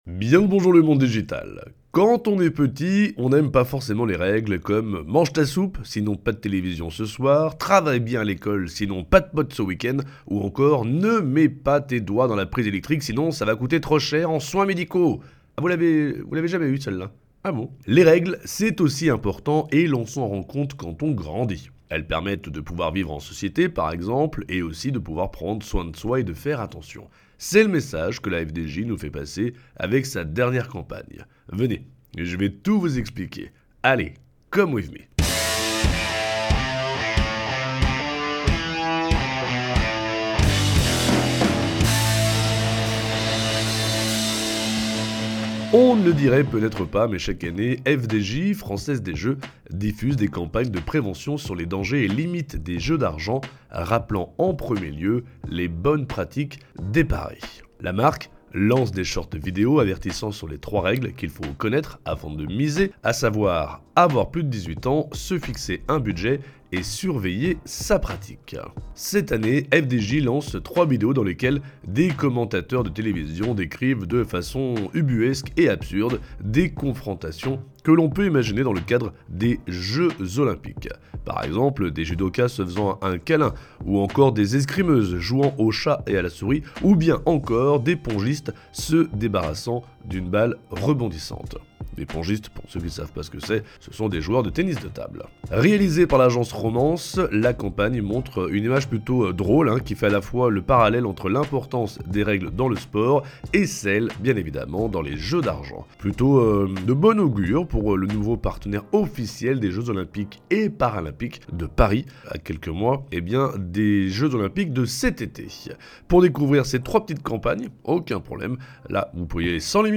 Cette année, FDJ lance trois vidéos dans lesquelles des commentateurs TV décrivent de façon absurde des confrontations que l’on imagine olympiques.